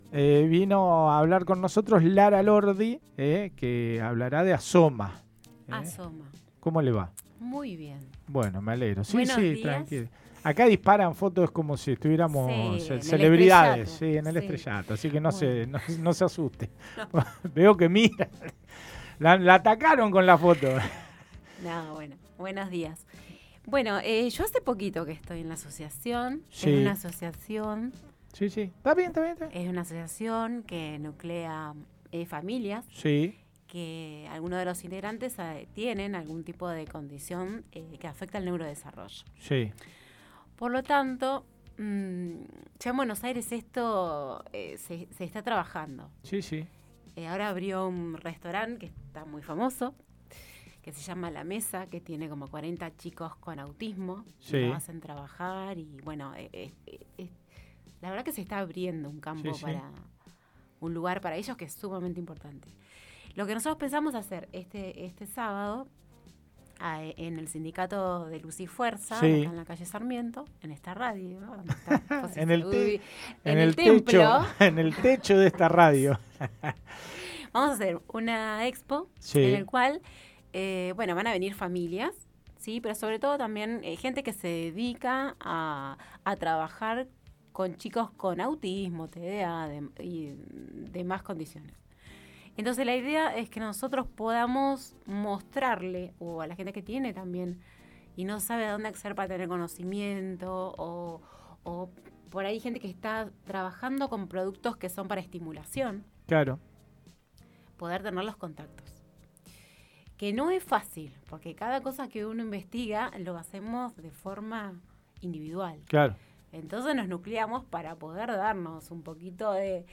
Este lunes, conversamos junto a la Asociación Asoma.